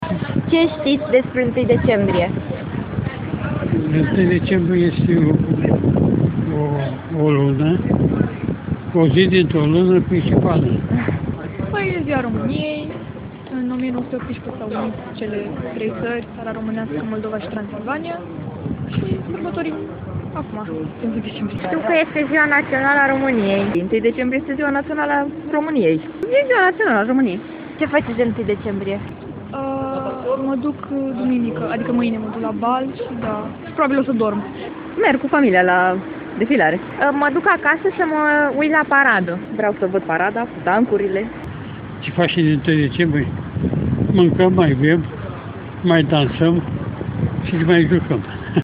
Interviu-1-DECEMBRIE.mp3